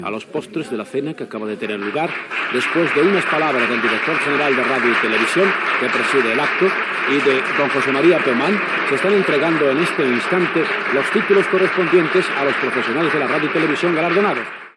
Lliurament en un hotel de Madrid de los Premios Nacionales de Radio y Televisión.
Declaracions de dos dels guardonats: Joaquín Prat, premi al millor locutor, i Jesús Álvarez García, premi de televisió.
Informatiu